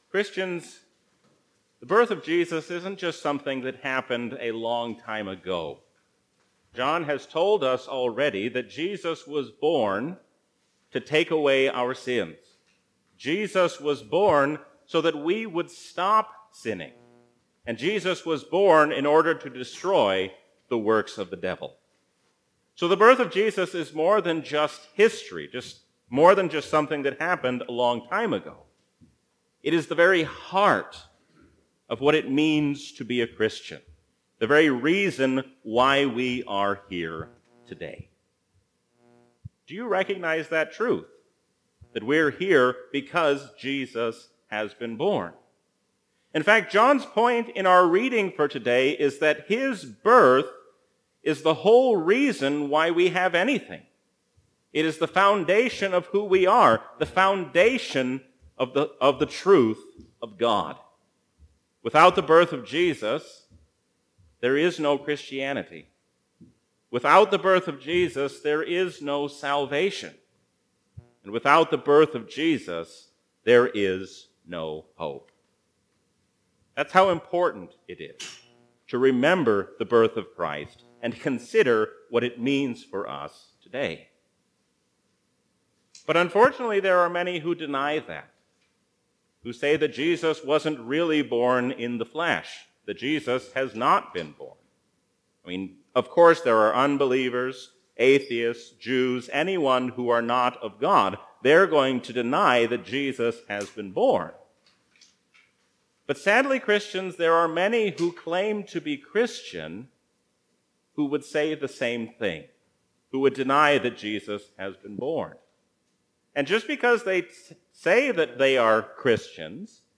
A sermon from the season "Advent 2021."